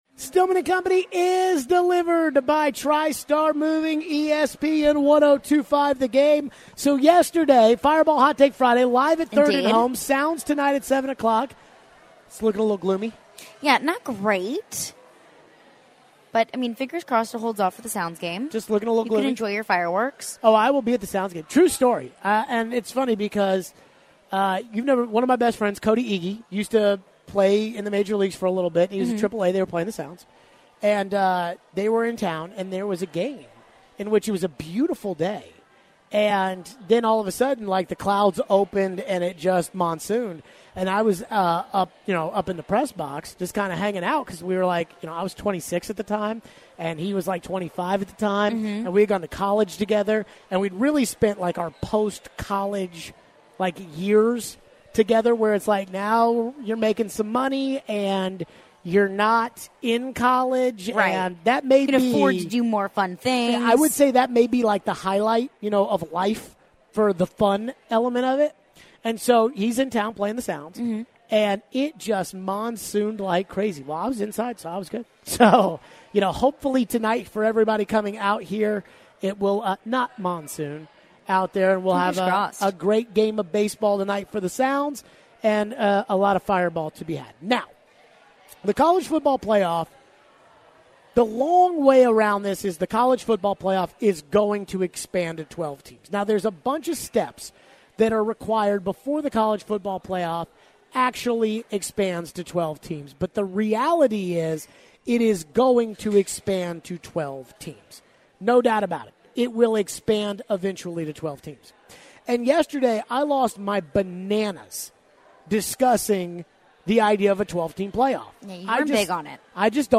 We take your phones.